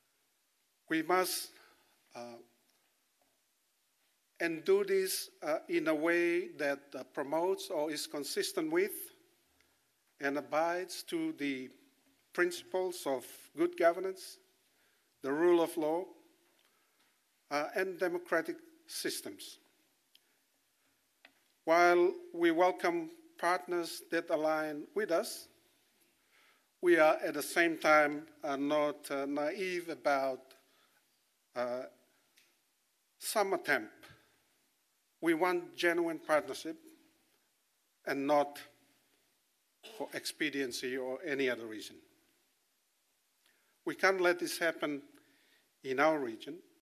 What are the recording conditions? The two speakers were addressing attendees at the Pacific Anti-Corruption Regional Conference in Sigatoka today.